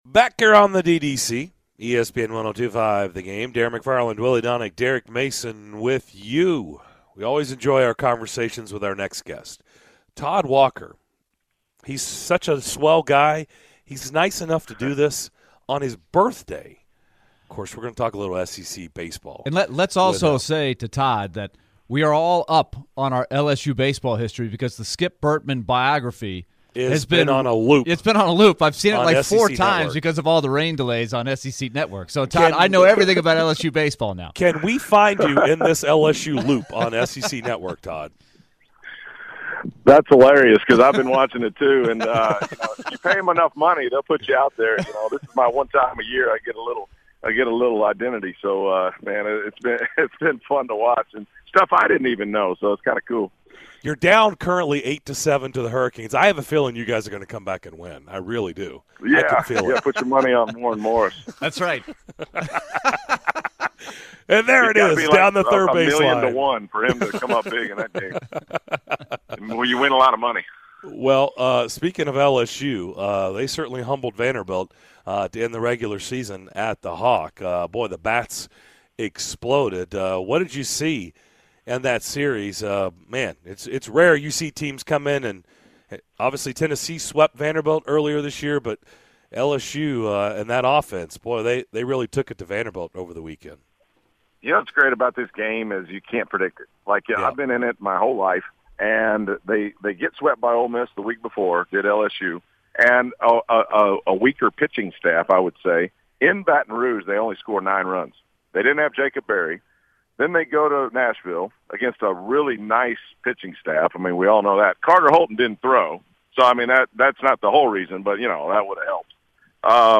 Former big league infielder and current SEC Network baseball analyst Todd Walker joins us on his birthday to talk SEC baseball. Topics include LSU's sweep of Vanderbilt, why the Commodores' outlook is positive, and how Tennessee should handle their massive expectations